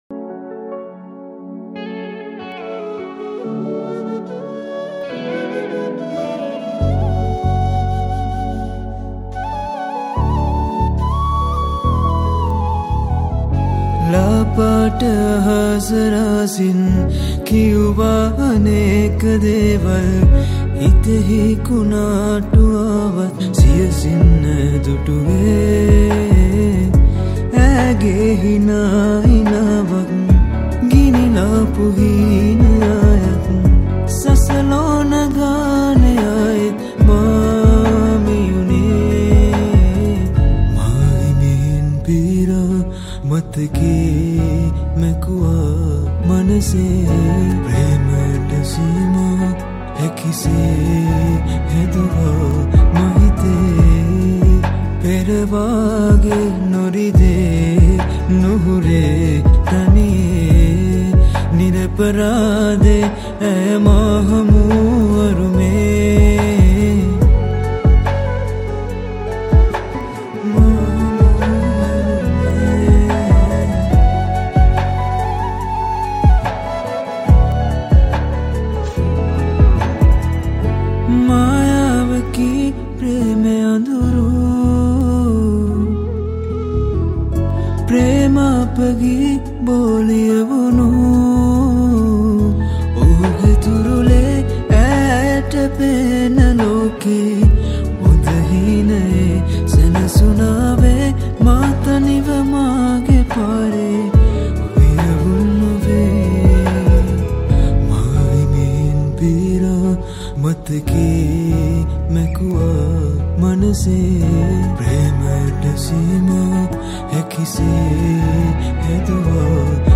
Guitars
Flutes